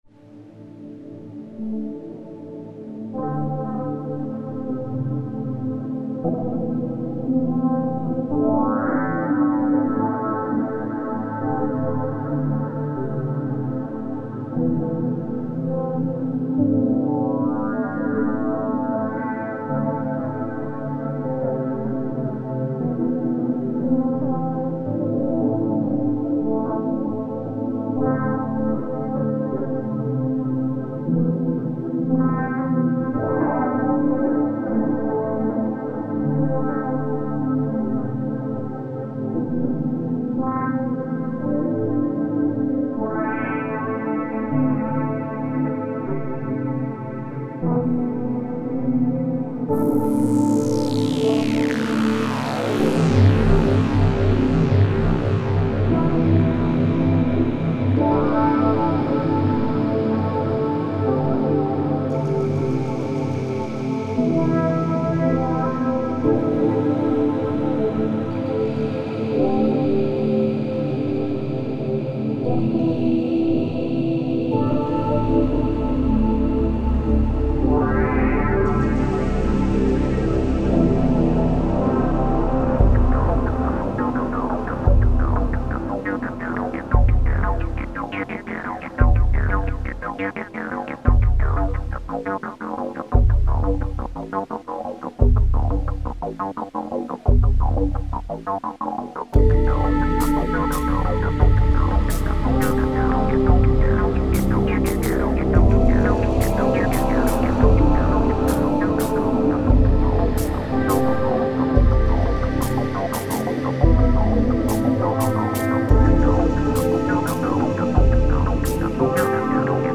Genre Acid